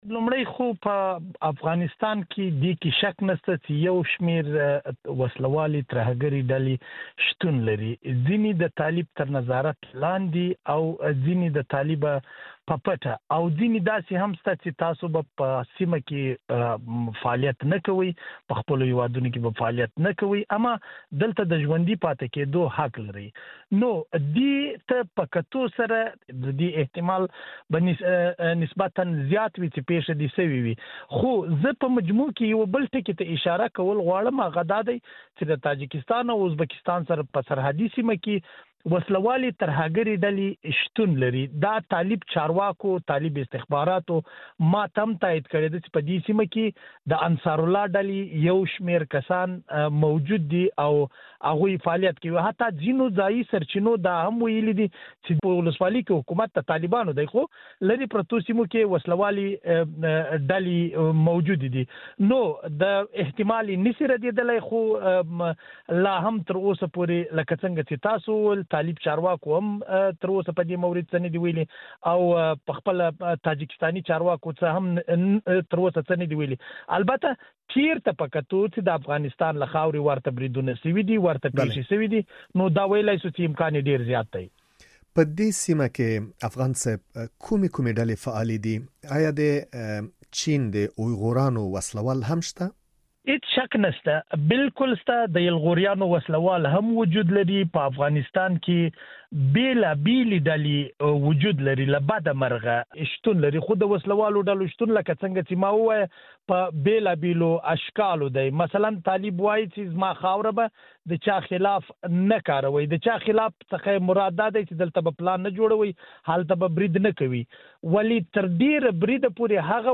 افغان سره مرکه